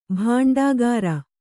♪ bhāṇḍāgara